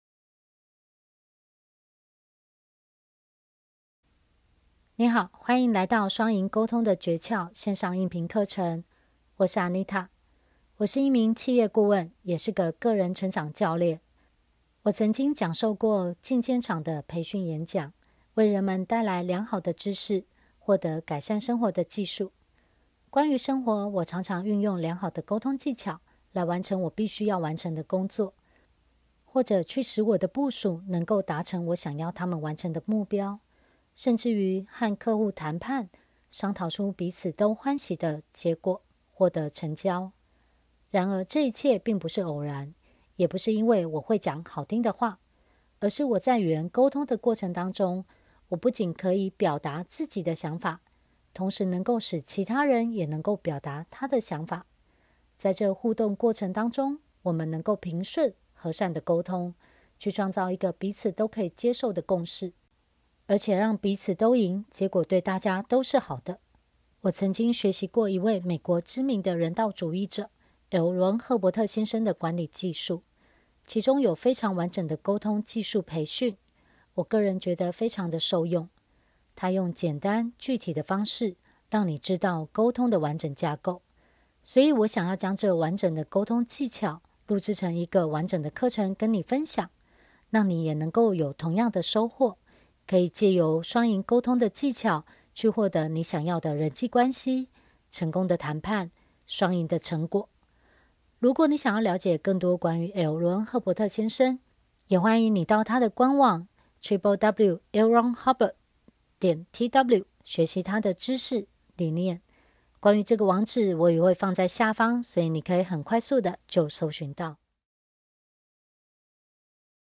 雙贏溝通的訣竅 線上音頻課程 36 個講座｜2 小時 37分鐘 1.